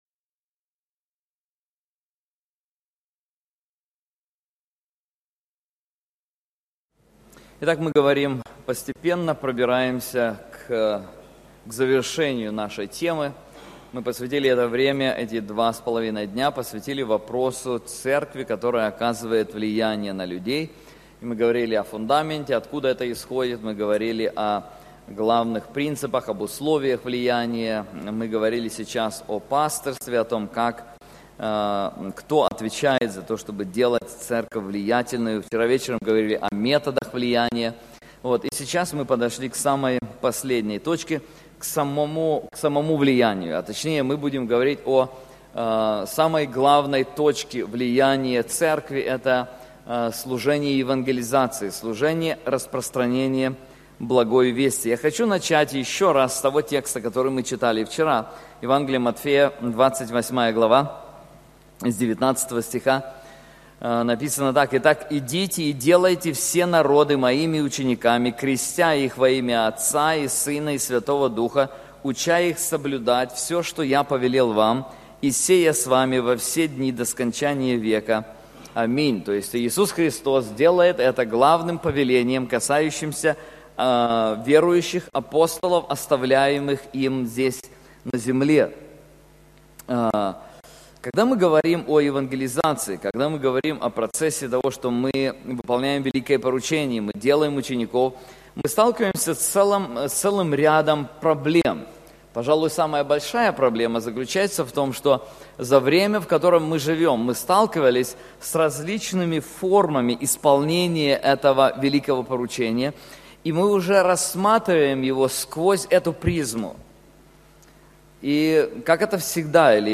Конференции Церковь, изменяющая мир